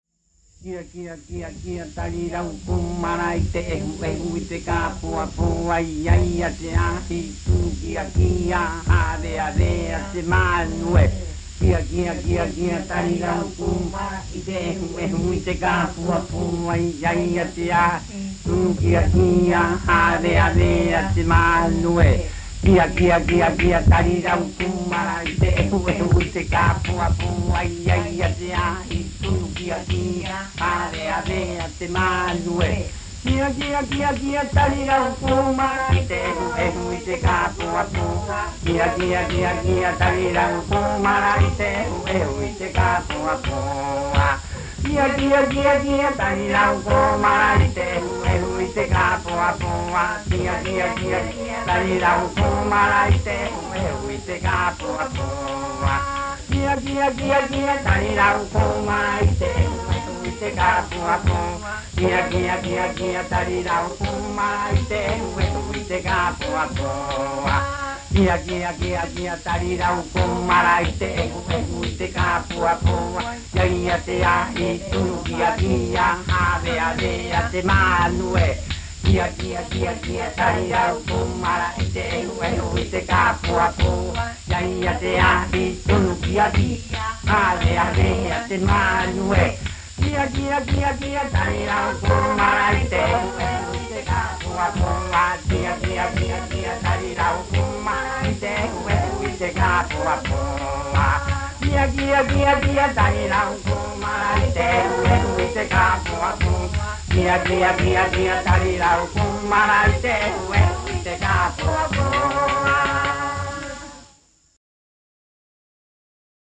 Recitado de Kai-Kai